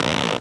foley_leather_stretch_couch_chair_15.wav